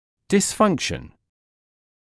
[dɪs’fʌŋkʃn][дис’фанкшн]дисфункция